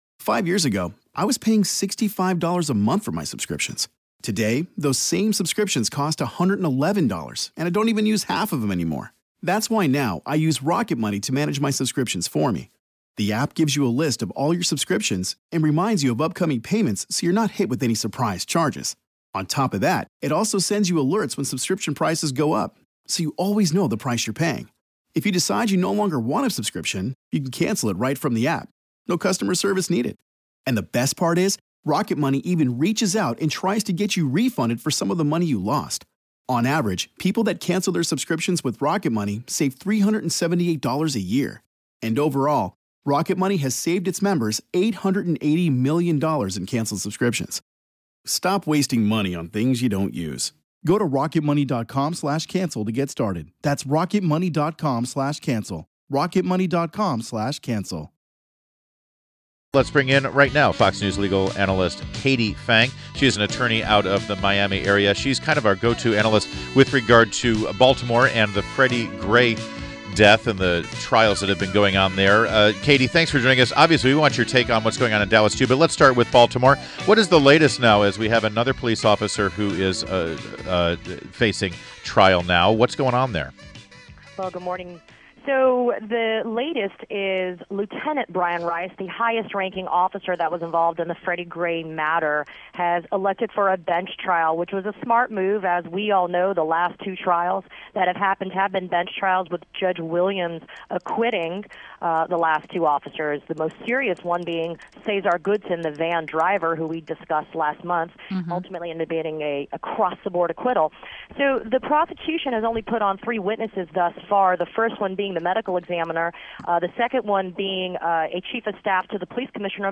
WMAL Interview - KATIE PHANG